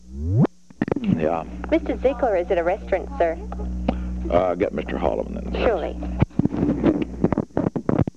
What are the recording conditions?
Secret White House Tapes Location: White House Telephone